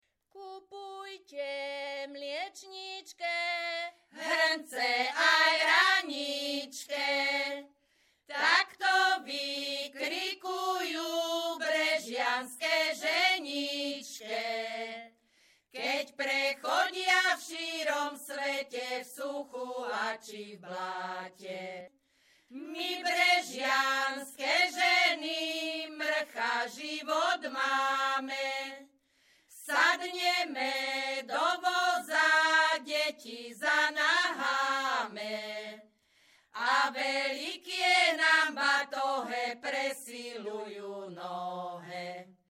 Descripton ženský skupinový spev bez hudobného sprievodu
Performers Spevácka skupina Hronka z Brehov
Place of capture Brehy
Key words ľudová pieseň